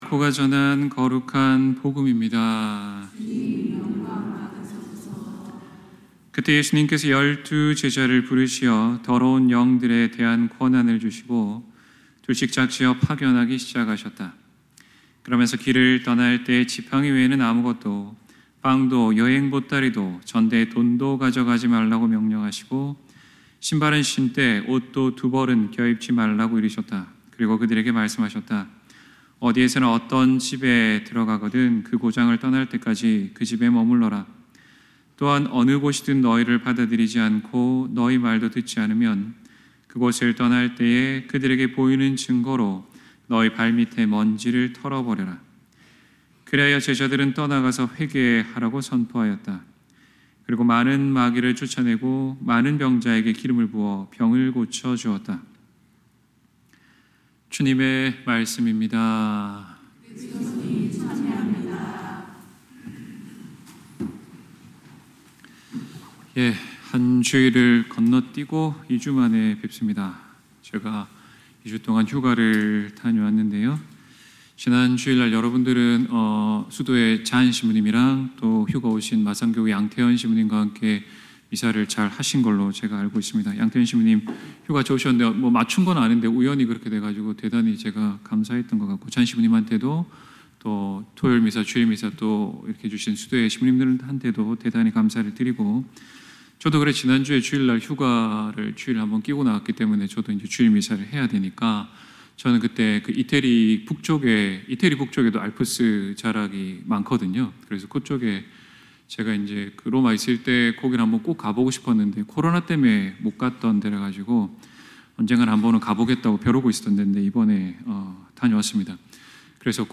2024년 7월 14일 연중 제15주일 신부님 강론